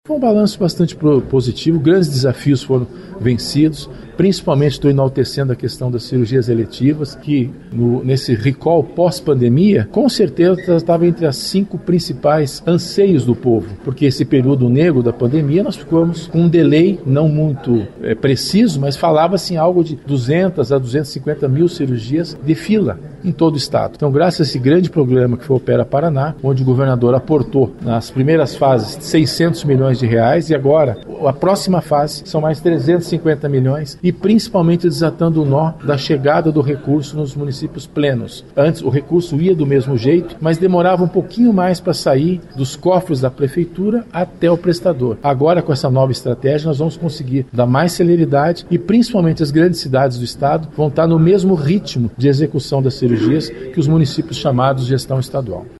A prestação de contas foi apresentada aos parlamentares da Assembleia Legislativa pelo diretor-geral e ex-secretário César Neves. Segundo o representante da secretaria, os investimentos são necessários para desafogar a fila de procedimentos gerada no período da pandemia de covid-19.